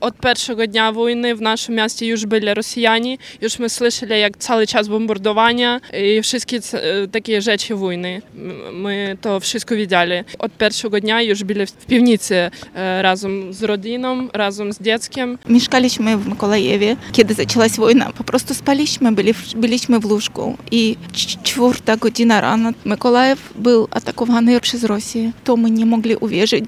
Wiec „3 lata w obronie Ukrainy i Europy” zgromadził mieszkańców Szczecina oraz ukraińskie rodziny, które znalazły tu schronienie przed wojennym terrorem. Uczestnicy wspominali pierwsze chwile wojny, które wciąż pozostają żywe w ich pamięci.